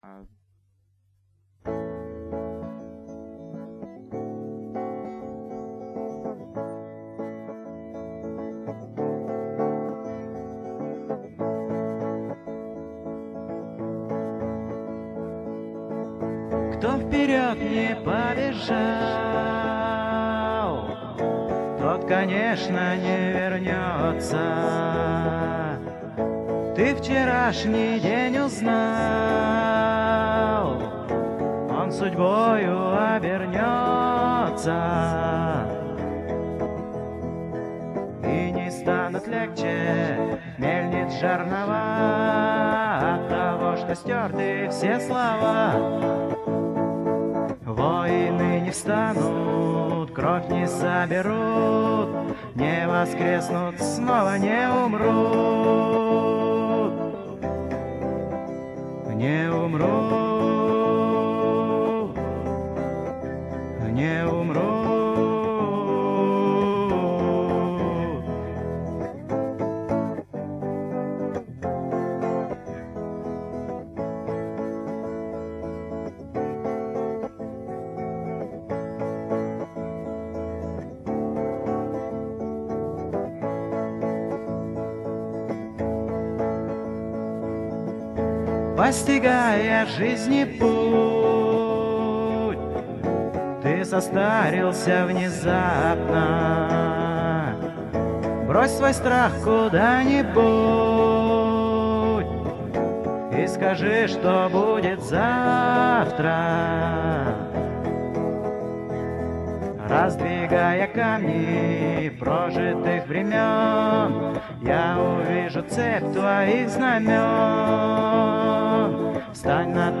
Все песни исполняет автор